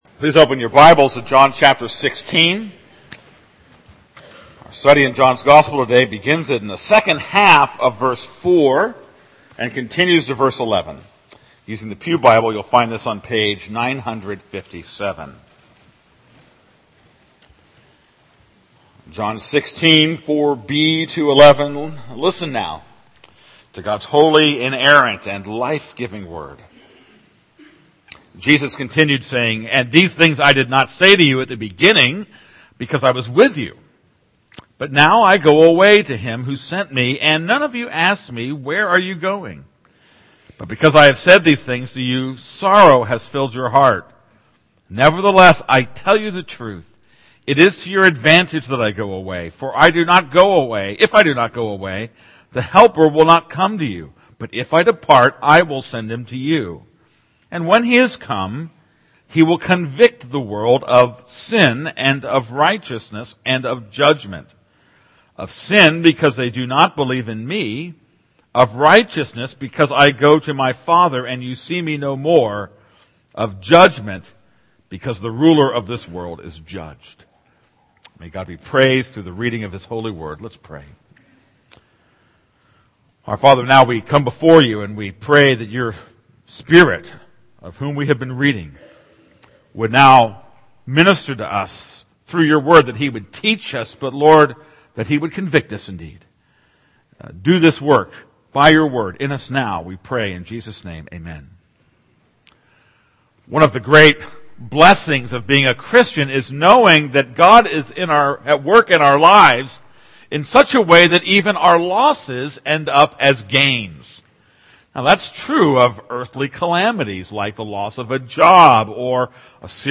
This is a sermon on John 16:4b-11.